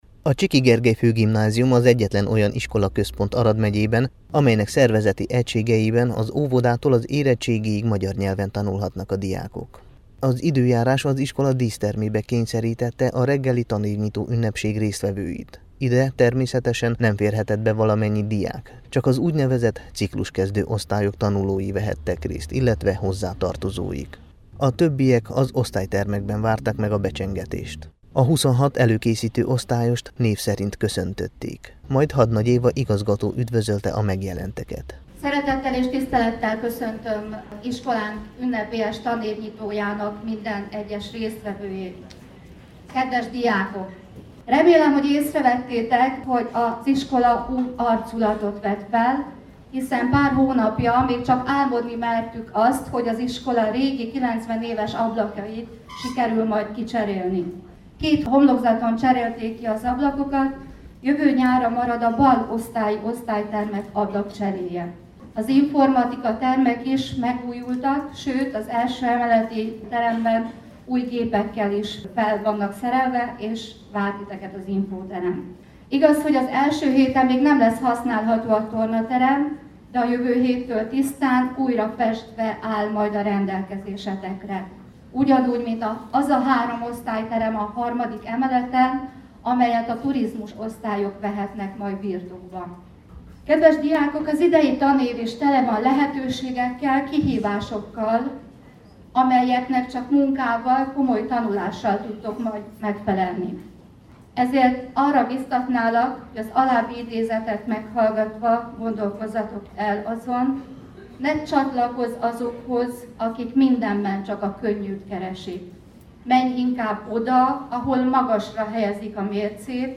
Tanévnyitó a Csiky Gergely Főgimnáziumban [AUDIÓ]